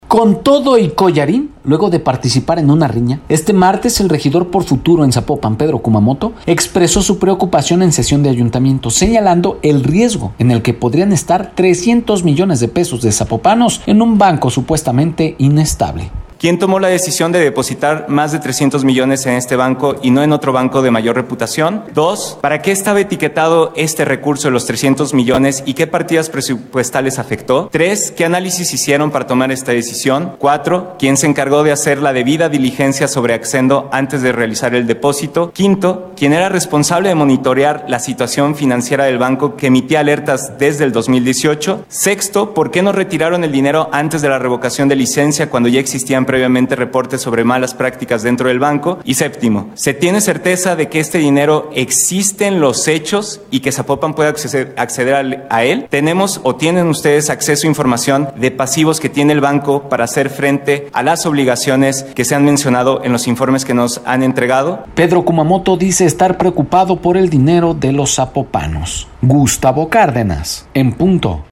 Con todo y collarín luego de participar en una riña, este martes el regidor por Futuro en Zapopan, Pedro Kumamoto, expresó su preocupación en sesión de ayuntamiento, señalando el riesgo en el que podrían estar más de 300 millones de pesos de los Zapopan en un banco supuestamente inestable.